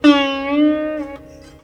SITAR LINE58.wav